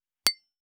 276,ガラスをあてる,皿が当たる音,皿の音,台所音,皿を重ねる,カチャ,ガチャン,カタッ,コトン,ガシャーン,カラン,カタカタ,チーン,
コップ